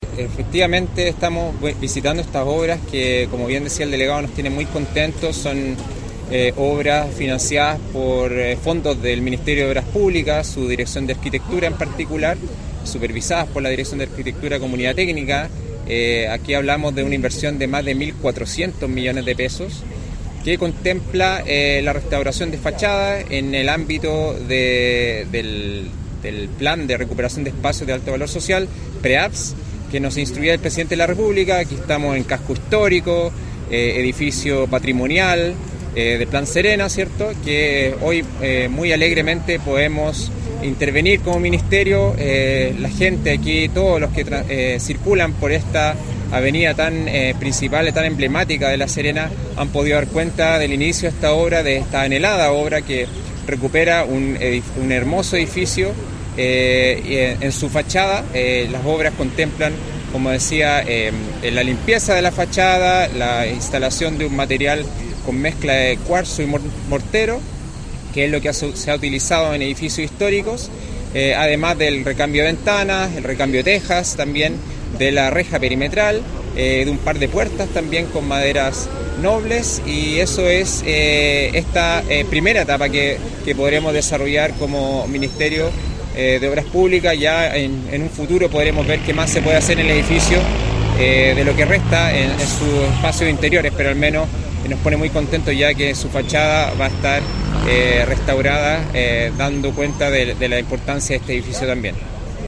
TIA-RICA-PREAVS-Javier-Sandoval-Seremi-MOP.mp3